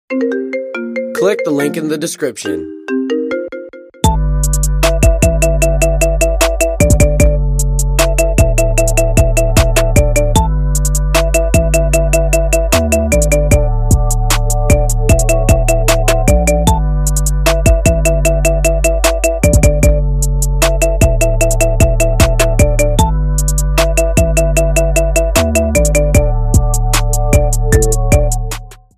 Kategorien: iPhone